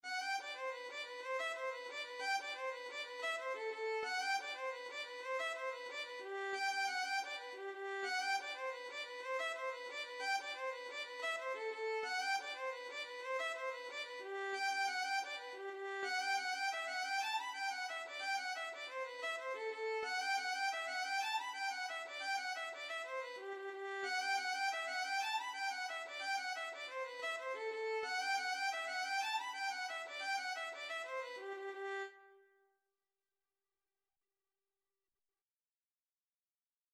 G major (Sounding Pitch) (View more G major Music for Violin )
6/8 (View more 6/8 Music)
Violin  (View more Intermediate Violin Music)
Traditional (View more Traditional Violin Music)
Irish